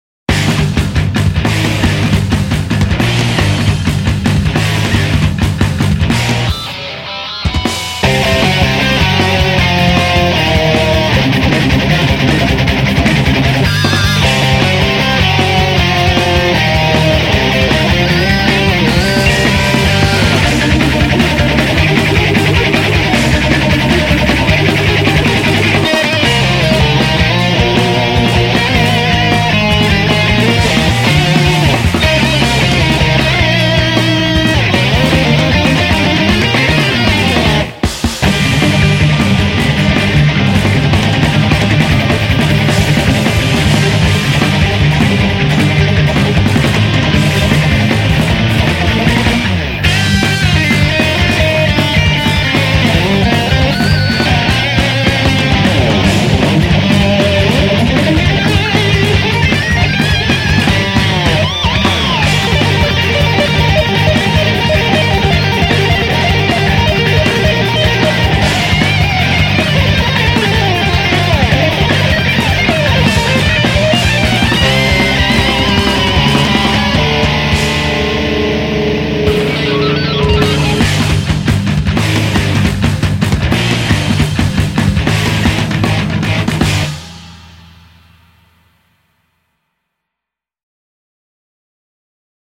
후반의 속주기타가 인상적입니다.